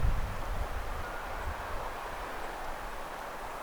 teltan kurkilauta ääntelee
varpuspöllömäisesti
En saanut siitä äänestä kunnon äänitystä.
teltan_kurkilauta_aantelee_varpuspollomaisesti.mp3